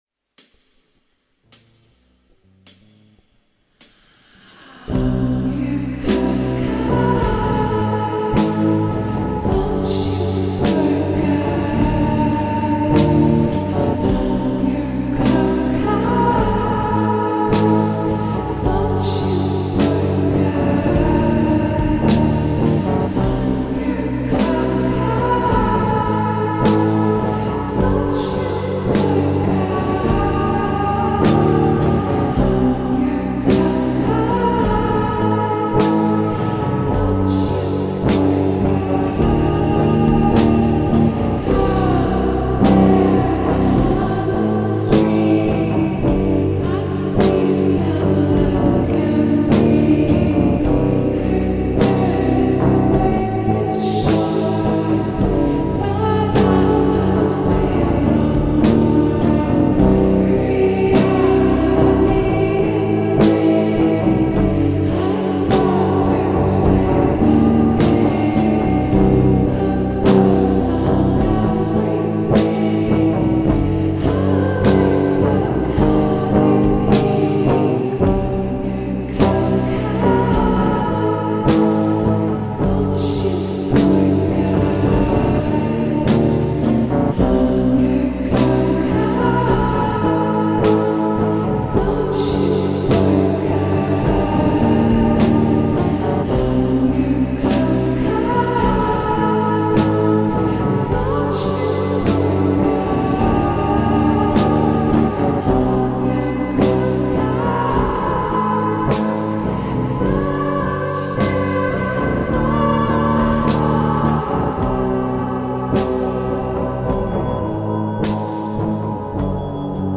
با ریتمی کند شده
عاشقانه